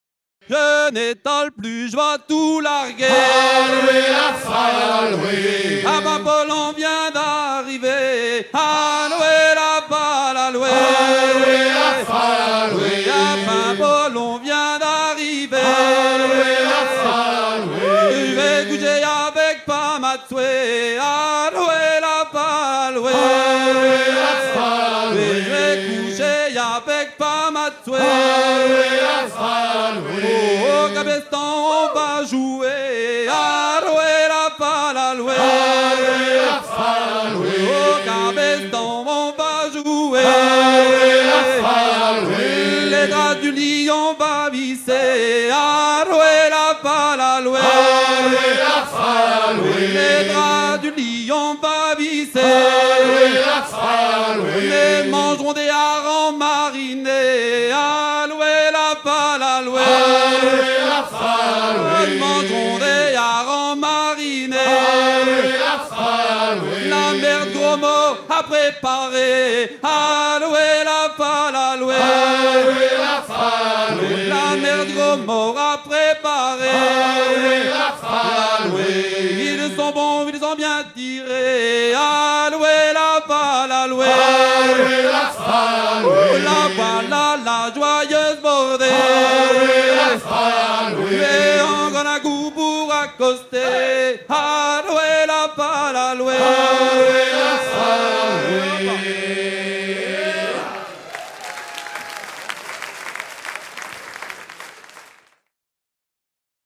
Paroles improvisées sur la mélodie d'un chant de halage fécampois, chant enregistré lors de Paimpol 99
Pièce musicale éditée